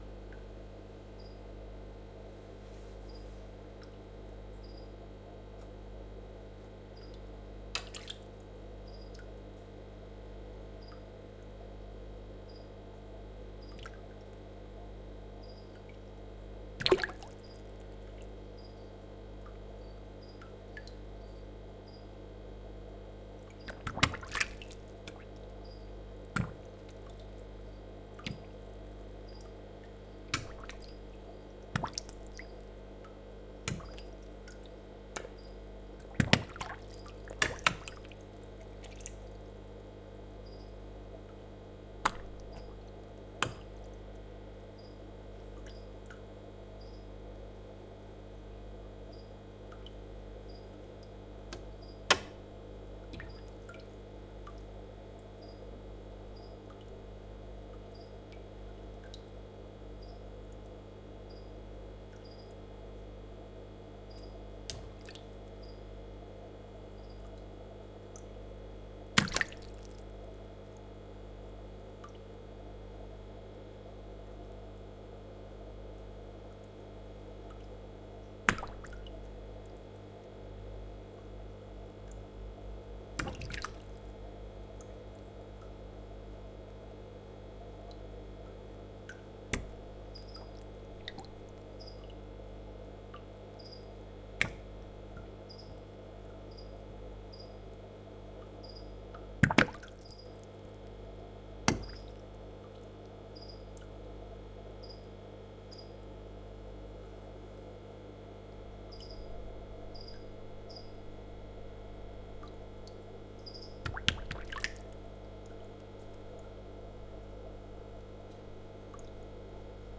etetes_huszcentisaranyhalak03.01.WAV